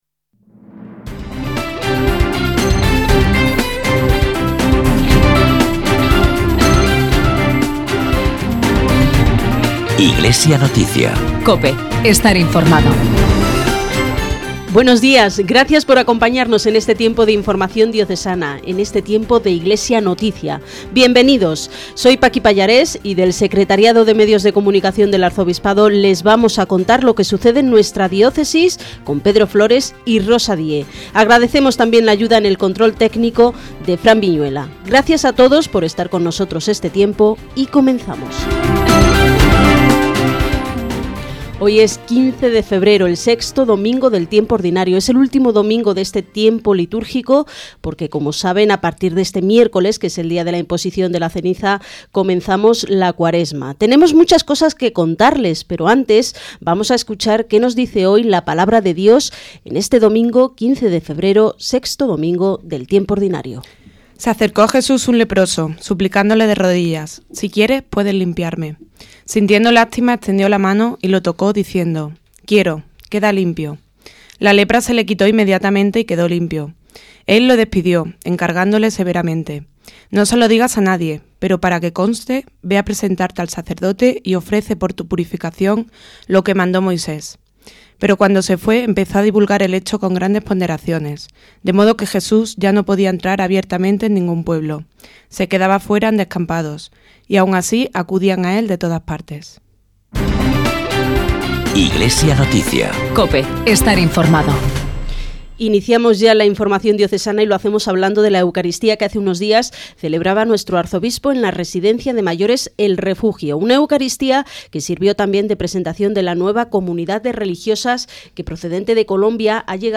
Iglesia Noticia es el programa informativo del Secretariado de Medios de Comunicación Social del Arzobispado que se emite cada domingo en COPE Granada a las 9:45 horas.